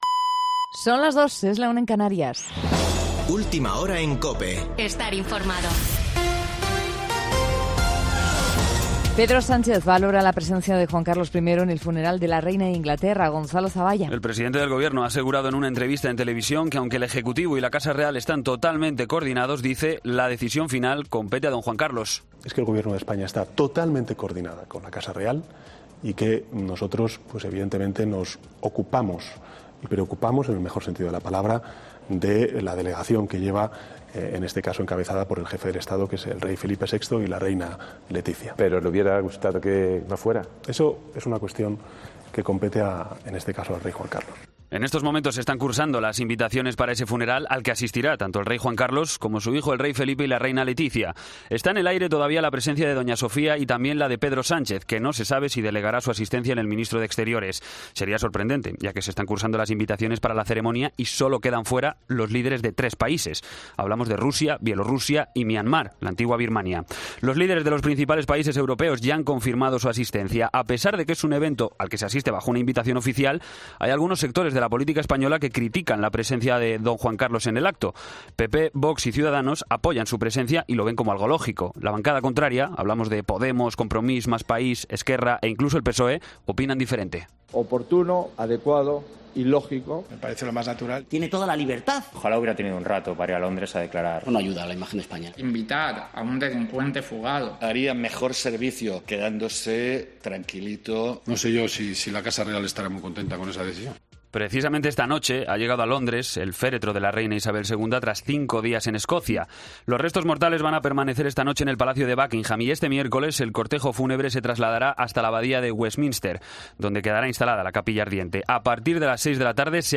Boletín de noticias COPE del 14 de septiembre a las 02:00 horas